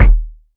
KICK.118.NEPT.wav